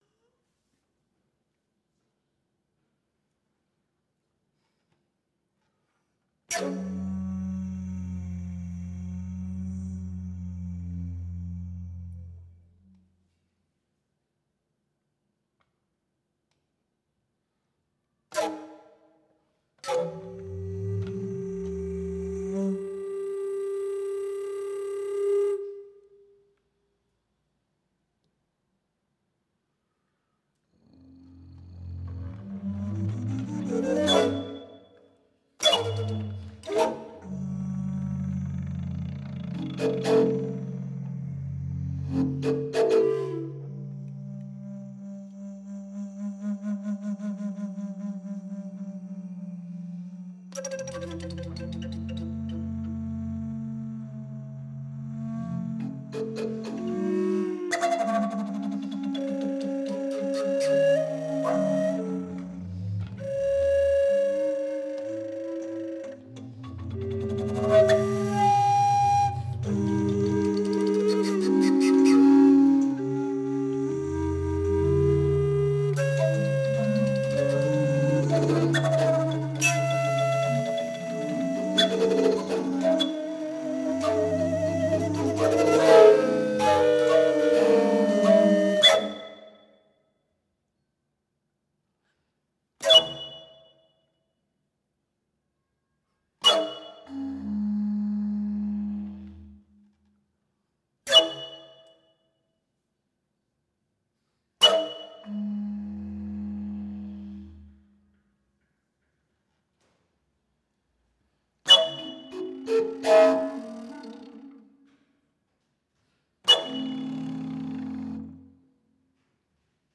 for six Paetzold recorders